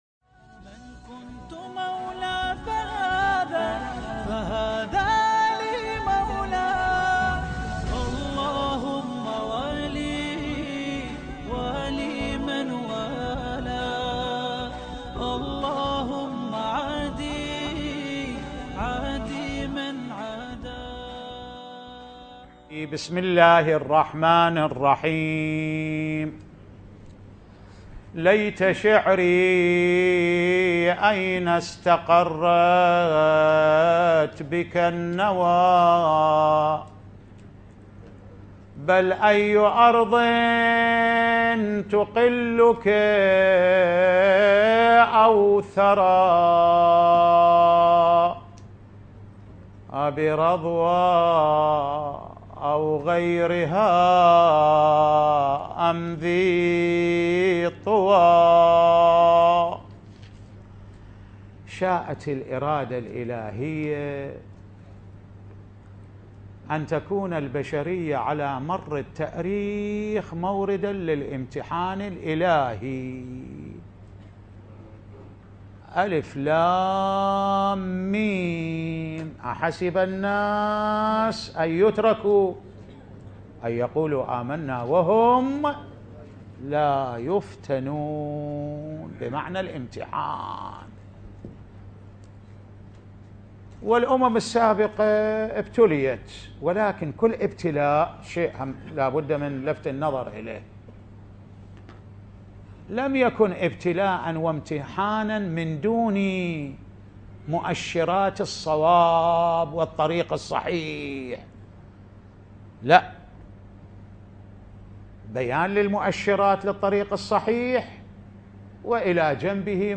الامتحان الالهي ـ محاضرة اخلاقية